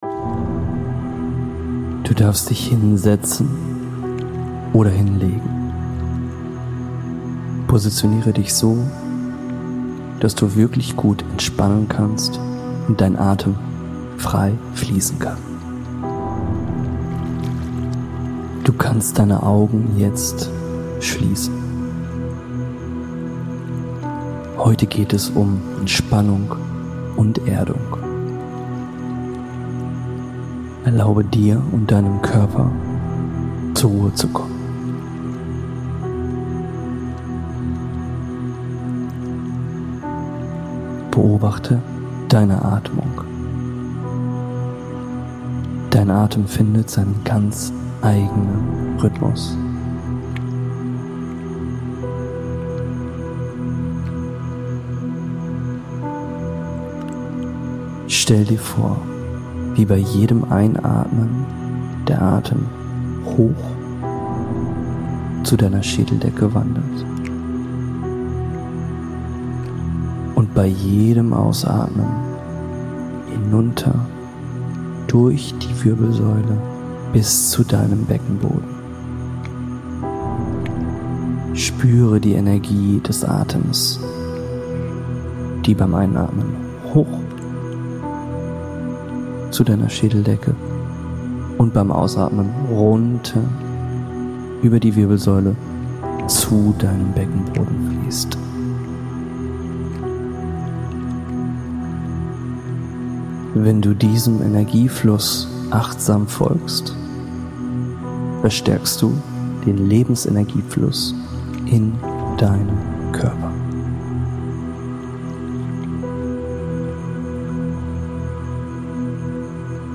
Durch beruhigende Atemübungen und bewusste Erdung, begleitet durch seichte Musik sowie ein OM Chanting, kannst du dich in kurzer Zeit neu zentrieren und ausgeglichener in den Alltag zurückkehren.